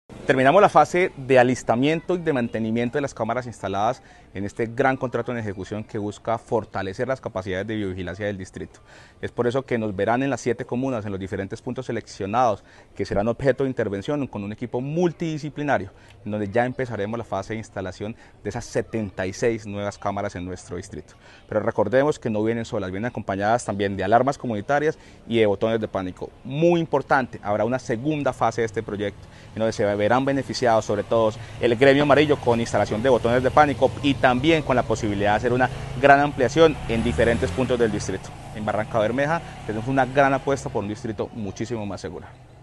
Eduardo Ramírez Alipio secretario distrital de Seguridad